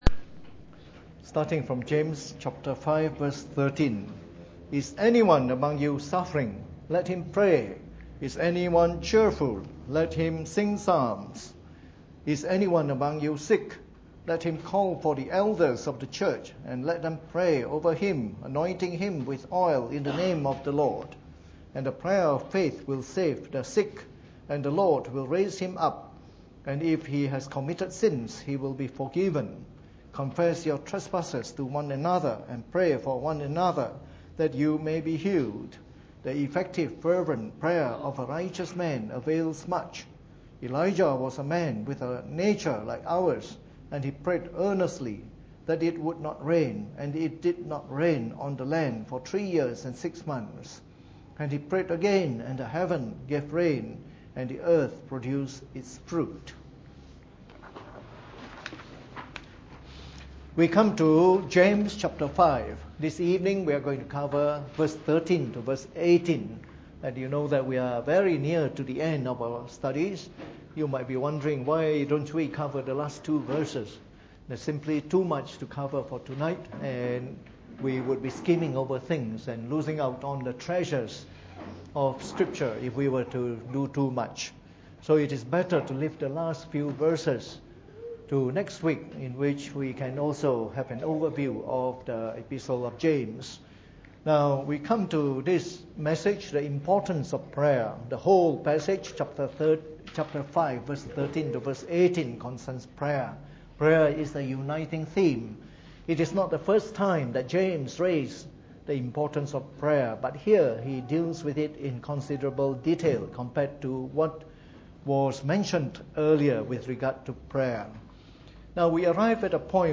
Preached on the 20th of January 2016 during the Bible Study, from our series on the Epistle of James.